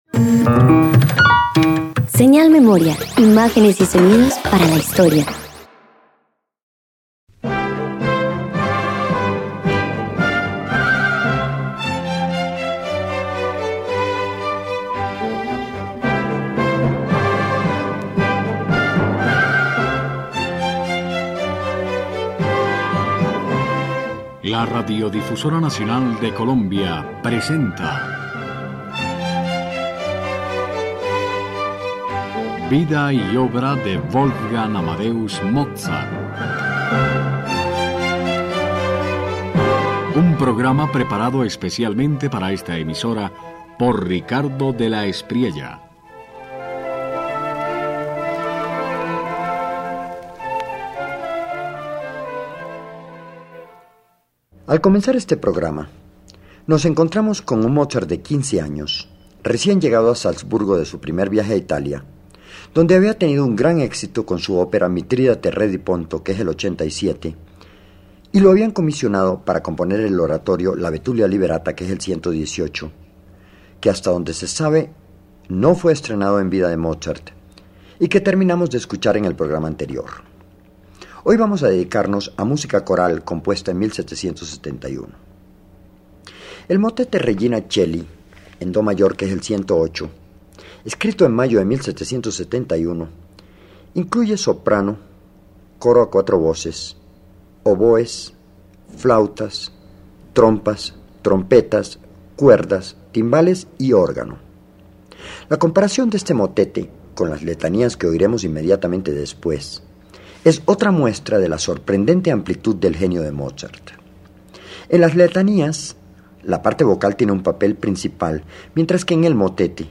Del oratorio a lo coral, Mozart despliega un “Regina coeli” con espíritu sinfónico: dos temas, breve desarrollo y brillo de metales. Las letanías lauretanas alternan solistas y coro, con orquesta discreta y modulaciones que sostienen el fervor.